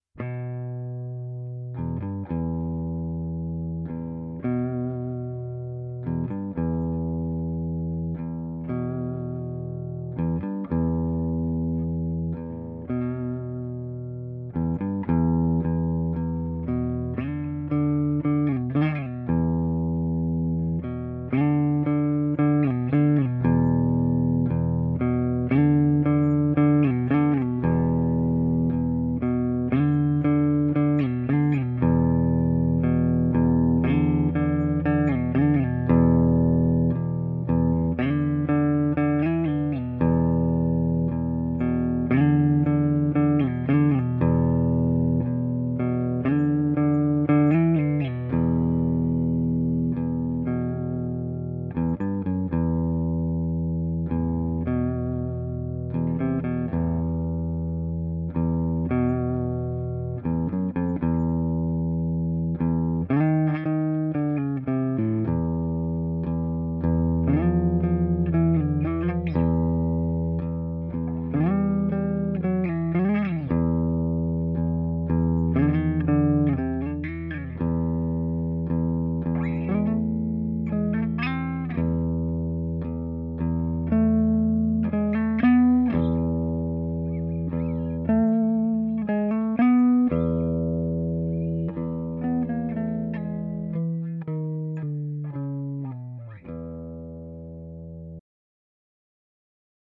描述：干净的音调，蓝调风格的即兴演奏，但几乎可以成为一首厄运/斯托纳摇滚歌曲的介绍。
Tag: 厄运 蓝调 摇滚 干净 即兴重复段 吉他 金属 电子